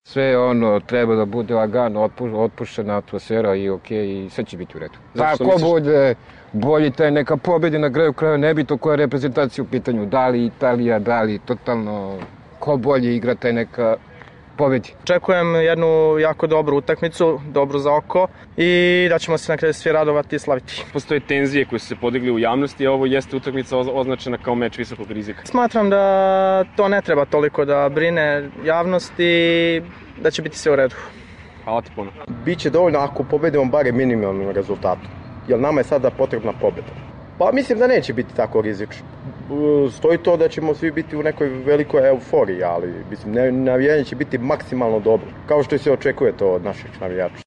Prethodila joj je slaba igra protiv Jermenije u subotu, zbog čega navijači sa kojima smo razgovarali očekuju bolju utakmicu, na kojoj ne bi trebalo da bude incidenata.